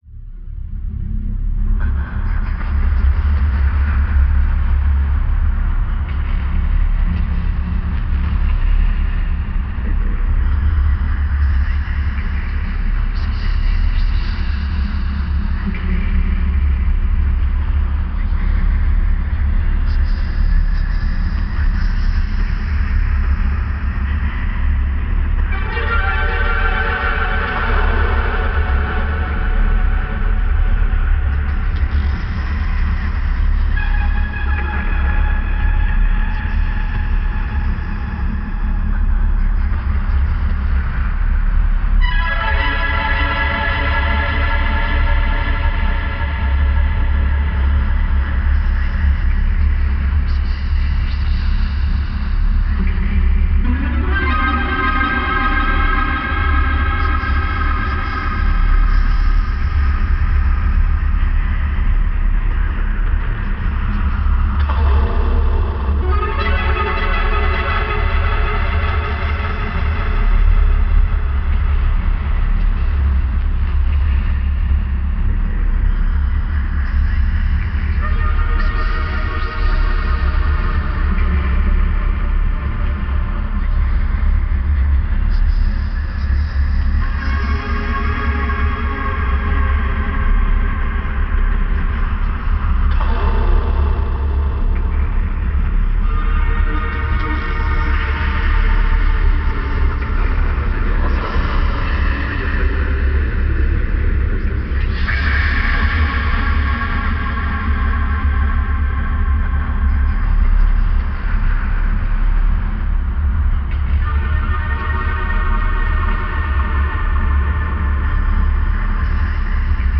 electronic ambient vhs horror dark